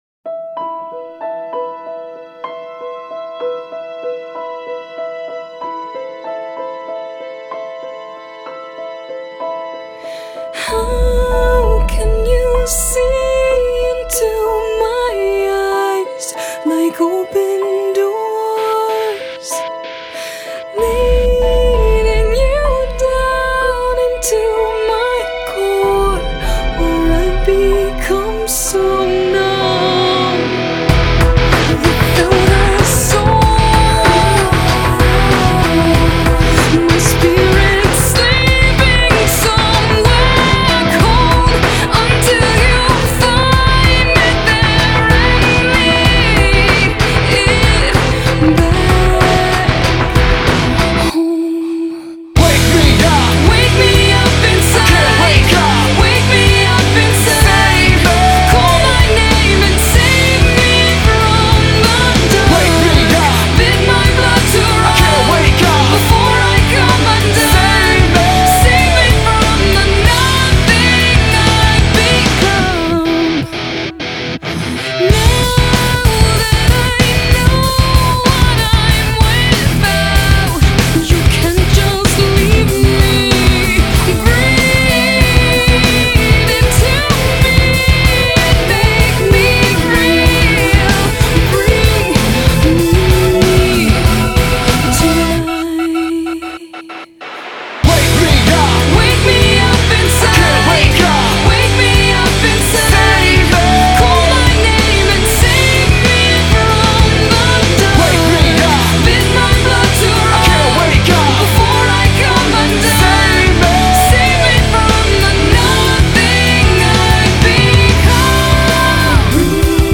Solo una voce femminile.. acuta. Insistente.
Esplode la batteria.